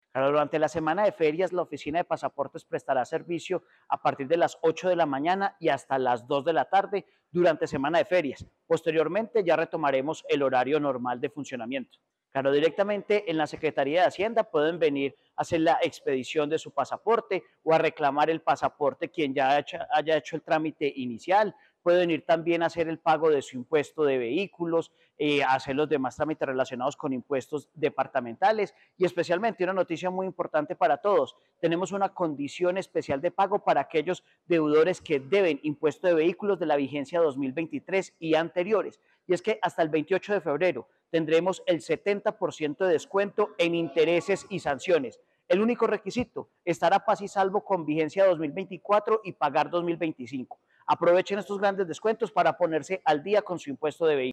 Secretario de Hacienda de Caldas, Jhon Alexander Alzate Quiceno.
Full-Jhon-Alexander-Alzate-Quiceno.mp3